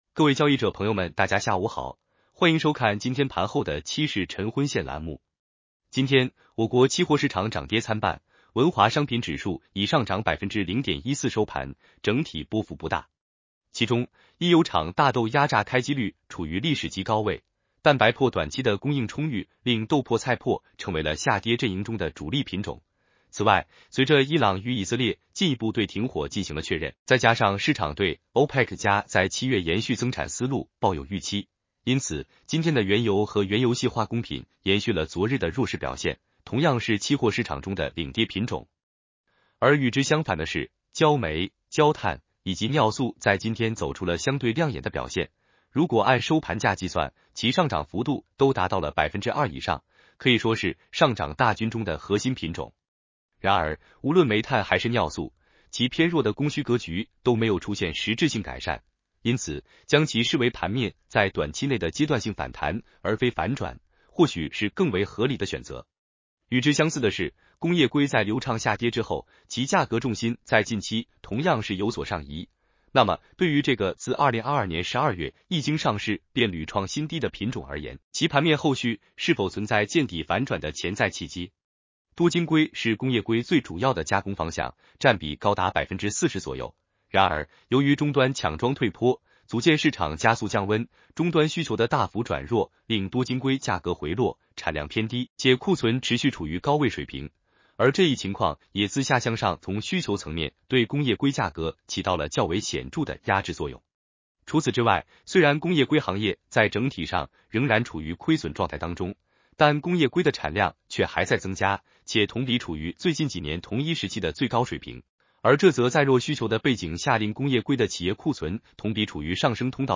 男生普通话版 下载mp3